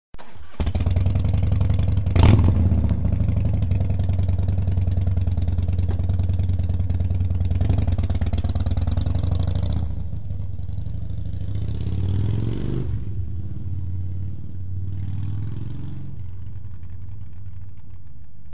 Klicka för att lyssna på HD med öppet system